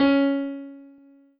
piano-ff-41.wav